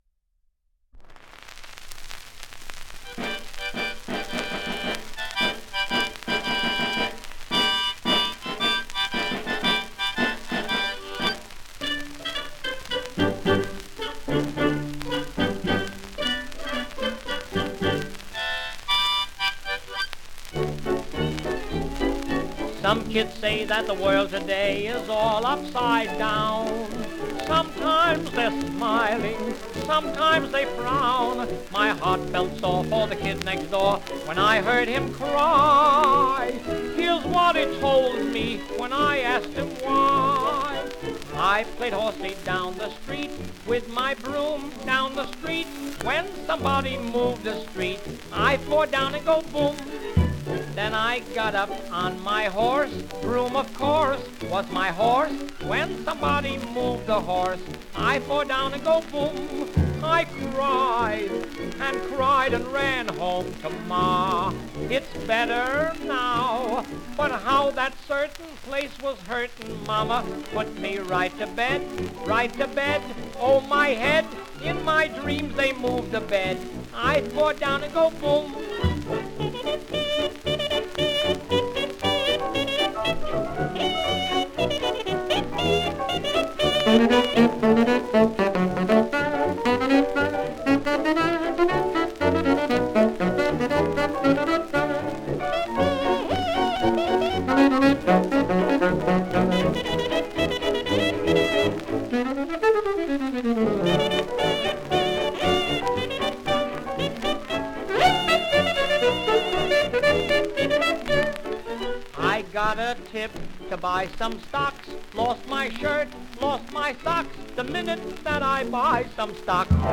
Genre: Popular Music.